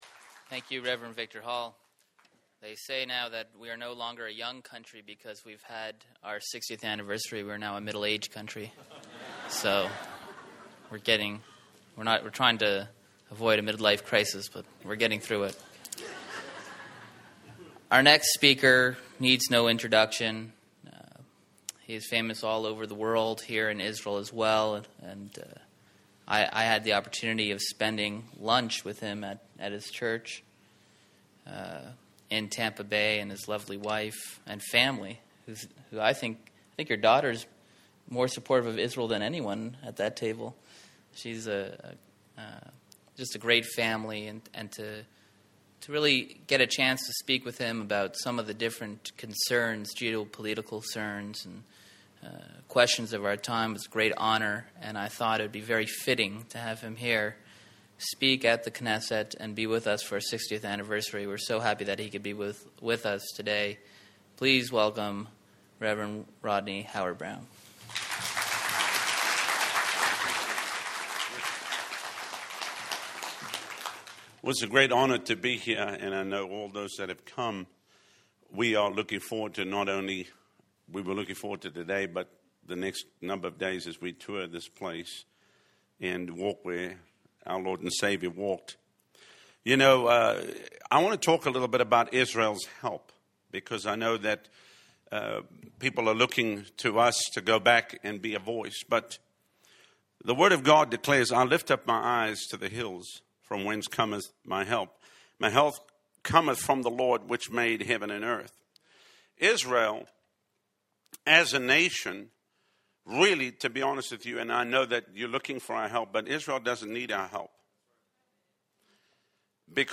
Speaks at the Knesset
KEY NOTE SPEAKER ISRAEL'S 60TH YEAR
prays in the Knesset